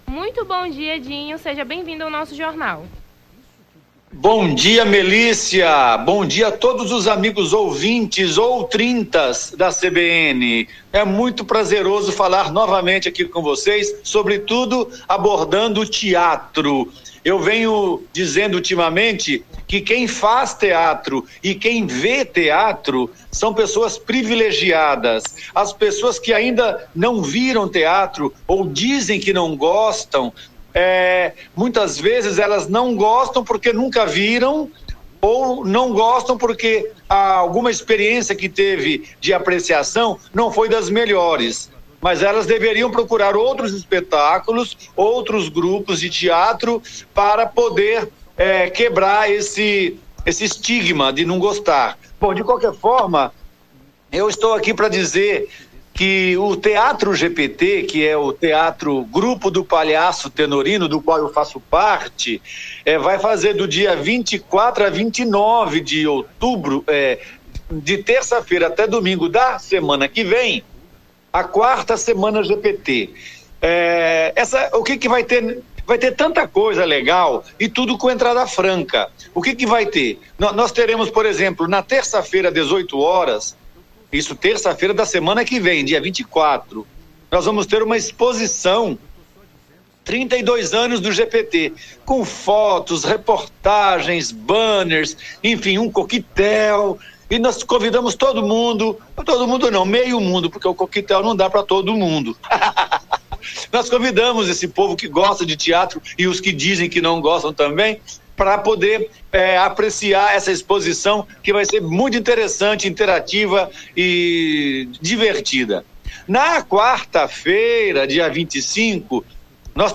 Nome do Artista - CENSURA - ENTREVISTA (TEATRO FETAC) 17-10-23.mp3